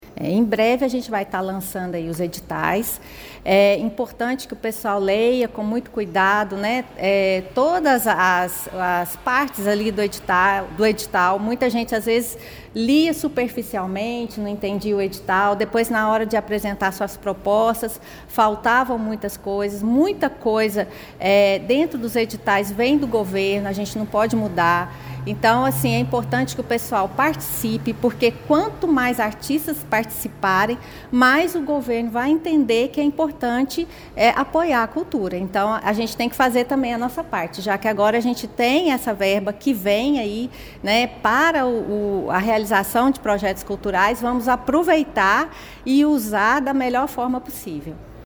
A secretária acrescenta que a PNAB é fundamental para fortalecer a produção cultural em Pará de Minas.